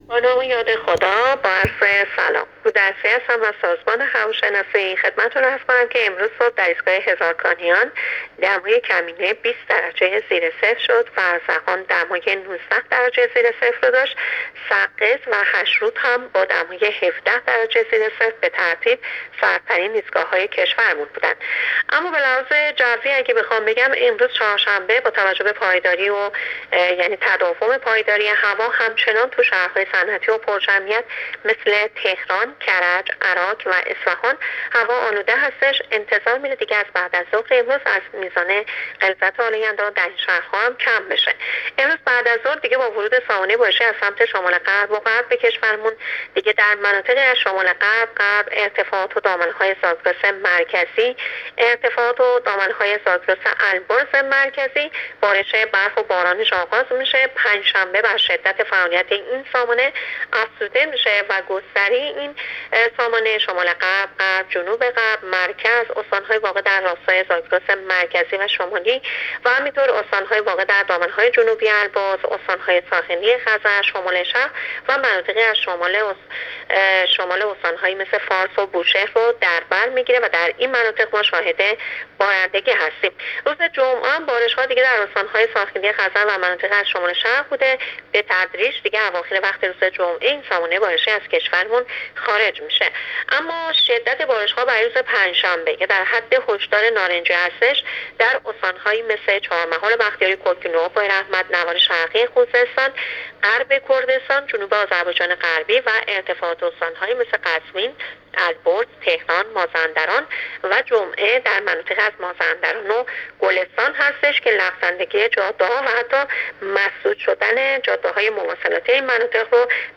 کارشناس سازمان هواشناسی کشور در گفت‌وگو با رادیو اینترنتی پایگاه خبری وزارت راه‌ و شهرسازی، آخرین وضعیت آب‌و‌هوای کشور را تشریح کرد.
گزارش رادیو اینترنتی پایگاه خبری از آخرین وضعیت آب‌‌و‌‌‌هوای ششم بهمن: